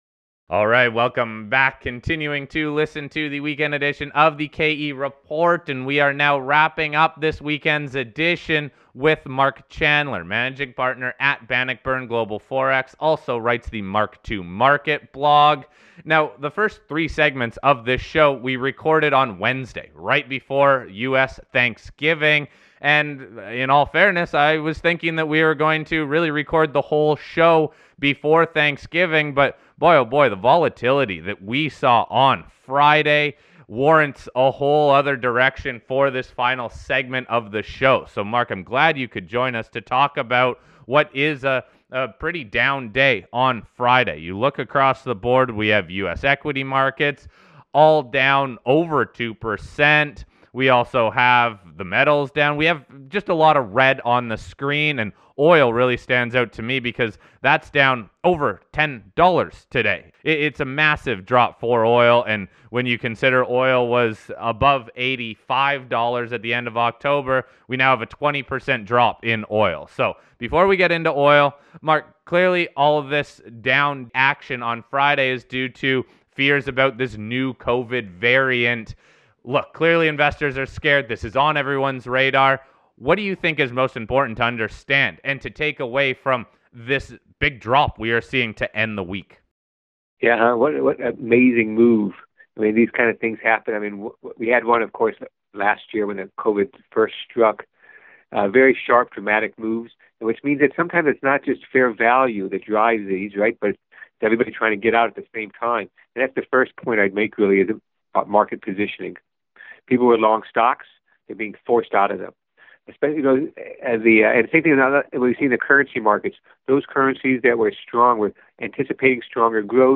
We are busy working on the Weekend Show but since today, even though it is a shortened trading day, we are seeing that largest drop in the Dow all year we wanted to release an interview focused on the market drop.